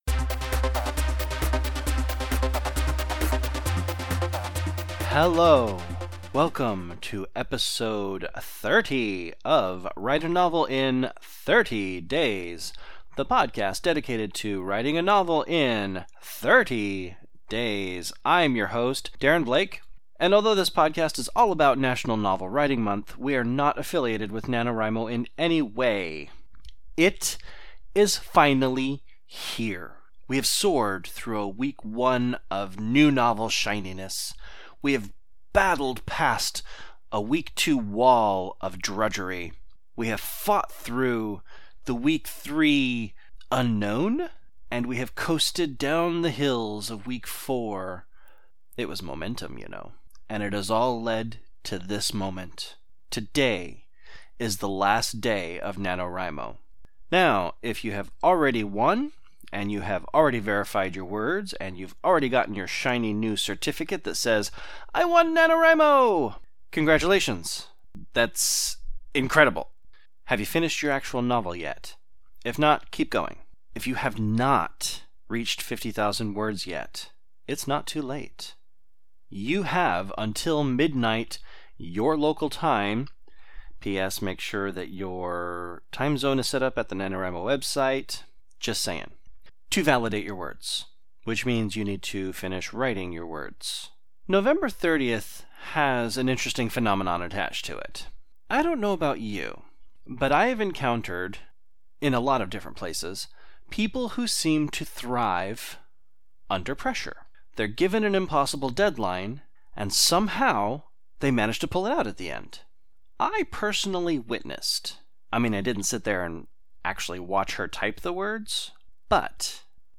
Music: “Raving Energy (faster)”